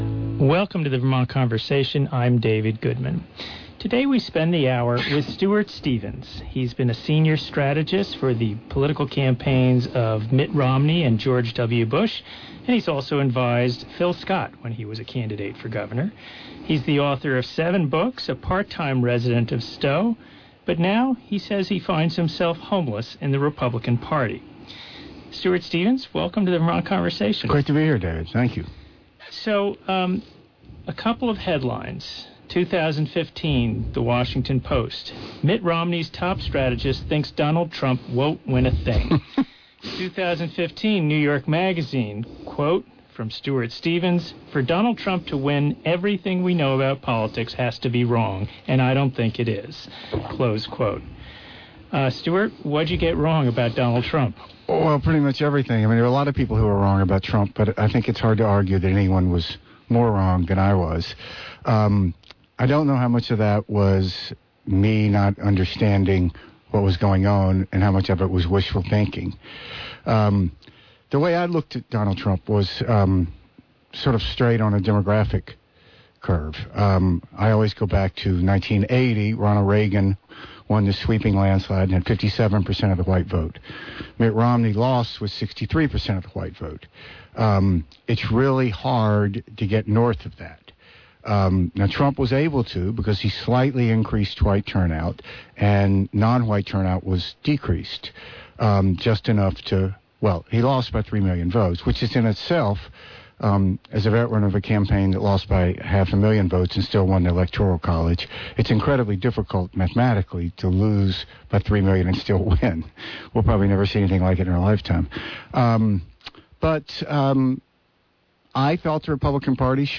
Stuart Stevens, former Republican strategist, author, It Was All a Lie: How the Republican Party Became Donald Trump